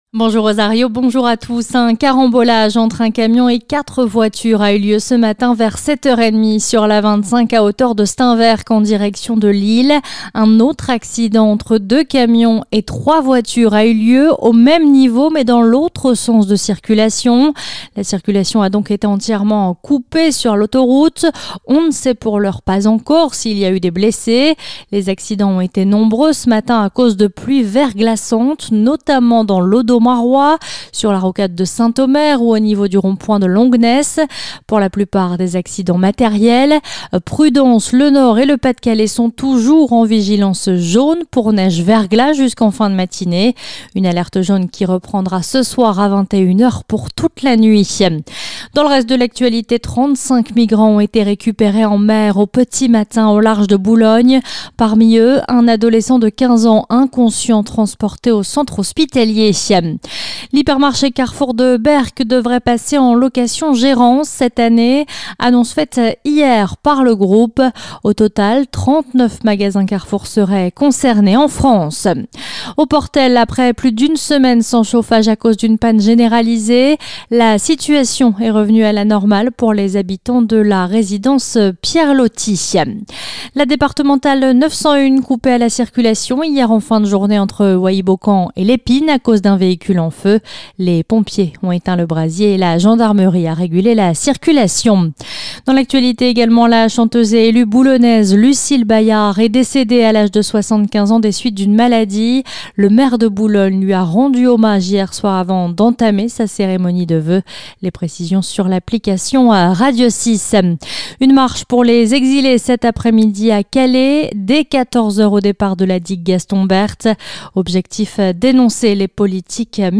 Le journal du samedi 11 janvier 2025